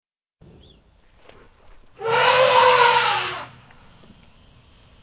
Elephant Roar Bouton sonore
The Elephant Roar sound button is a popular audio clip perfect for your soundboard, content creation, and entertainment.